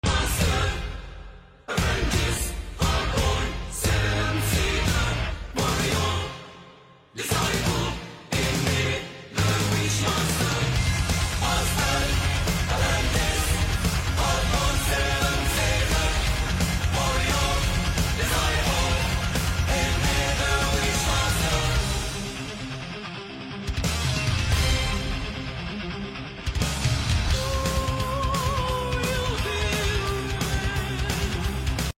vocals
guitars
keyboards
drums TRACK LIST 1.